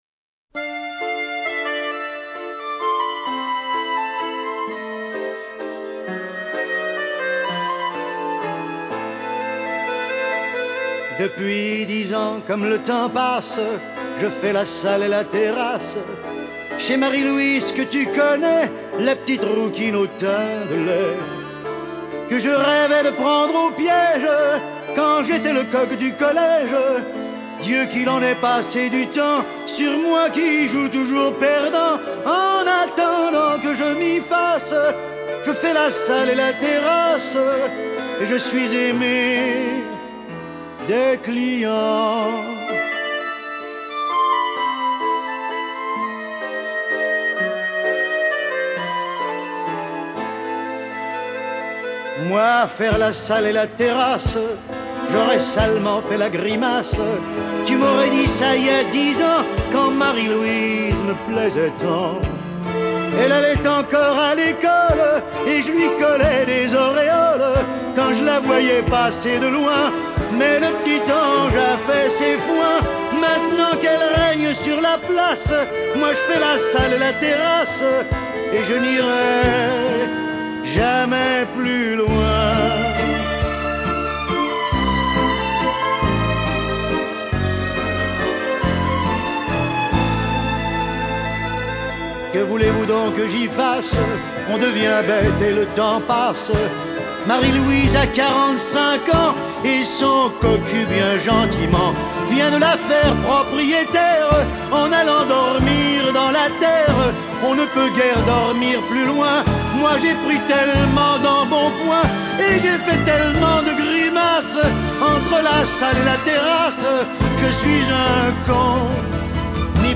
Вот красивая песня, жаль, качество не очень!